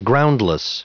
Prononciation du mot groundless en anglais (fichier audio)
Prononciation du mot : groundless